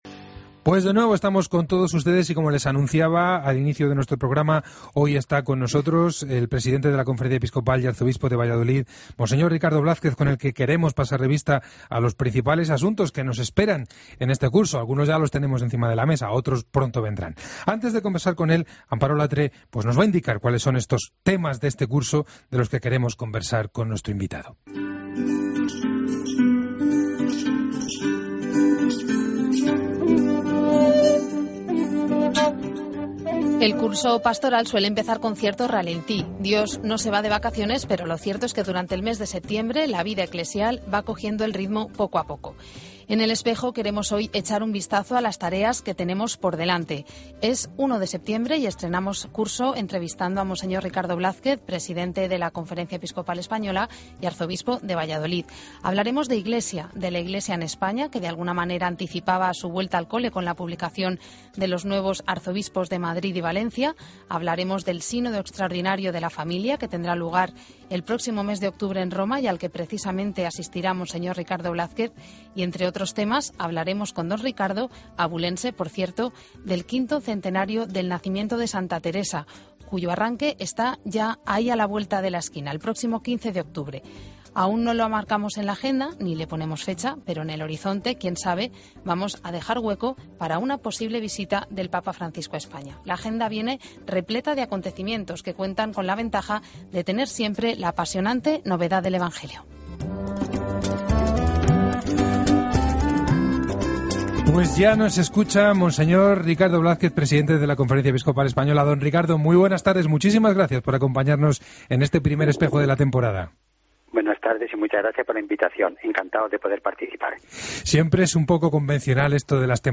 Escucha la entrevista a Monseñor Ricardo Blázquez en El Espejo